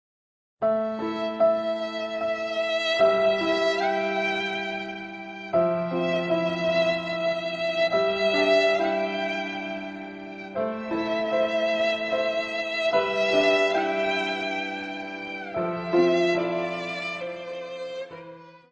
funny sad music Meme Sound Effect
funny sad music.mp3